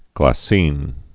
(glă-sēn)